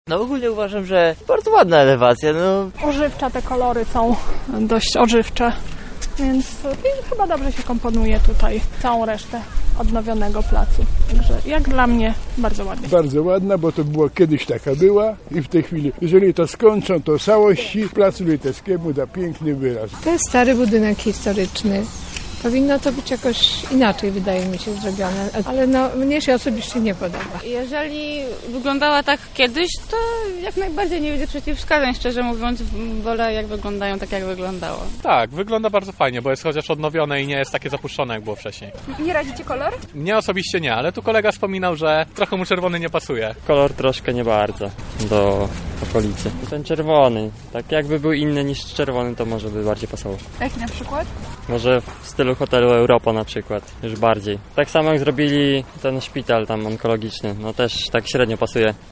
zapytała mieszkańców Lublina, co sądzą na temat efektów tej renowacji.